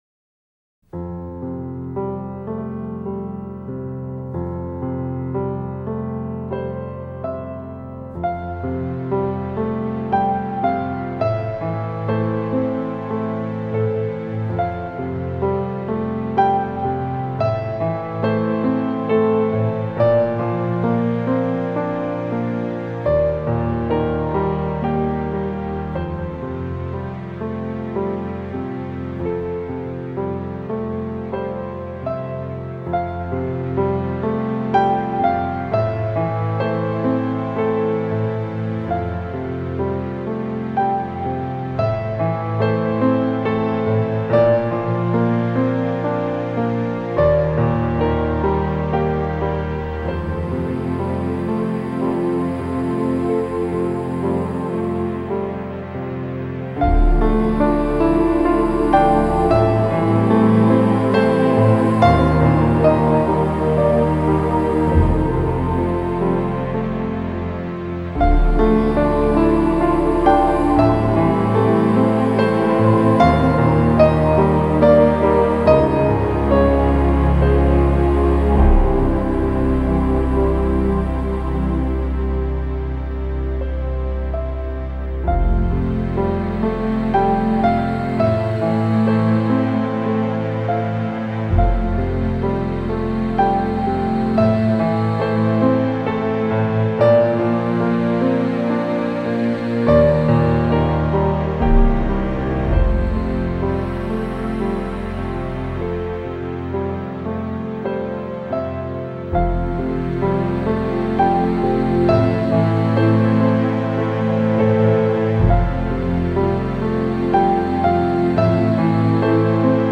那另外的一首钢琴曲